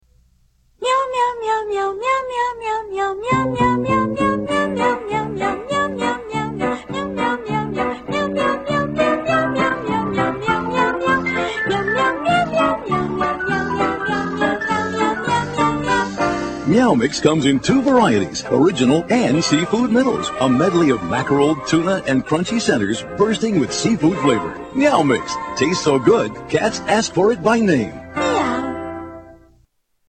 Tags: Media Infinitum Absurdum Repetitious Humor Experiment Funny Repeated words